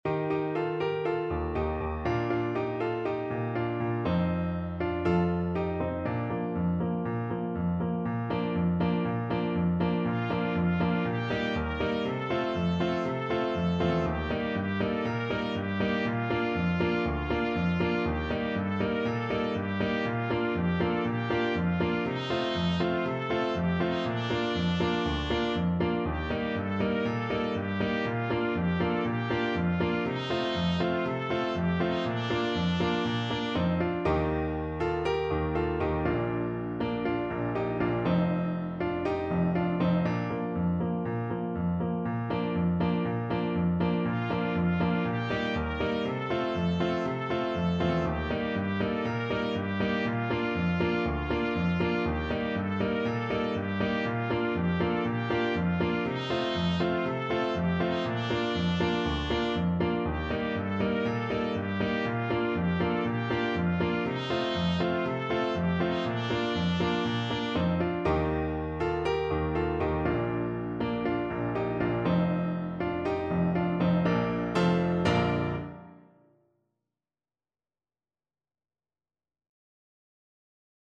Trumpet
Traditional Music of unknown author.
Bb major (Sounding Pitch) C major (Trumpet in Bb) (View more Bb major Music for Trumpet )
D5-Bb5
Allegro (View more music marked Allegro)
2/4 (View more 2/4 Music)
Classical (View more Classical Trumpet Music)
Croatian